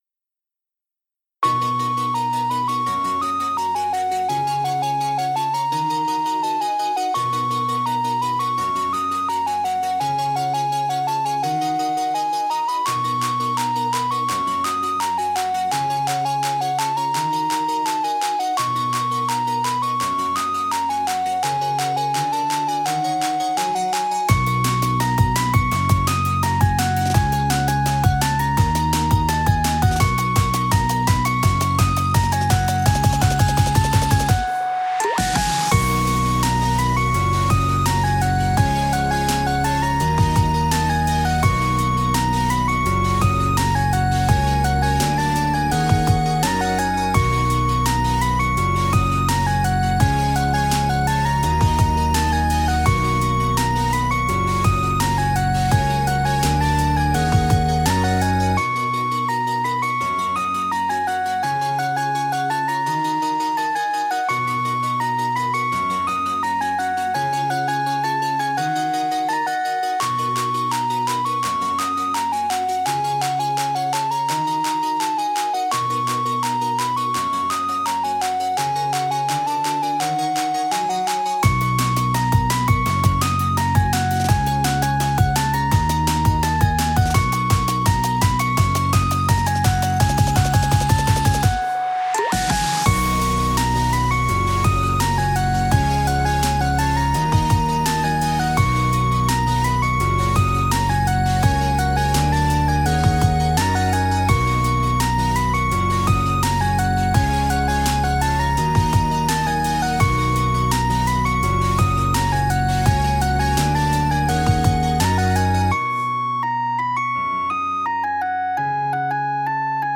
ほのぼのとした雰囲気の明るいEDM調のBGMです。